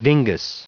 Prononciation du mot dingus en anglais (fichier audio)
Prononciation du mot : dingus